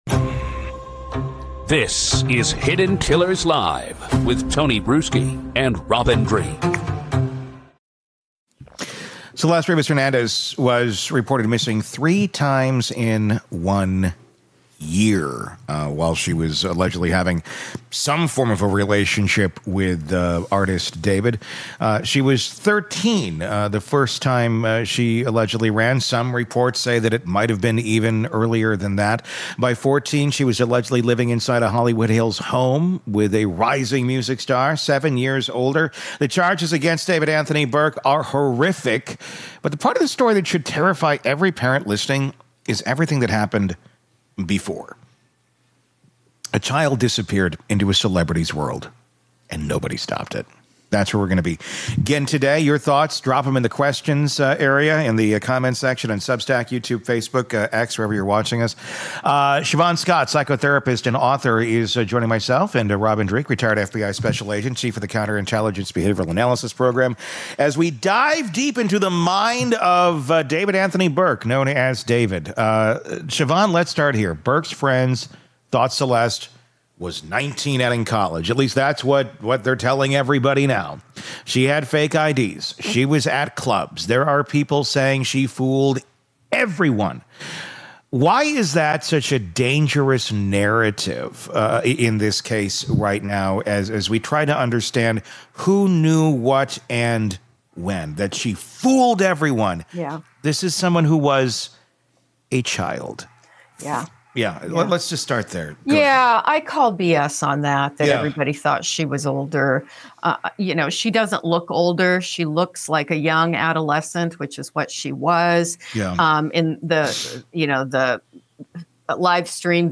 D4VD: The Interview That Goes Where Others Won't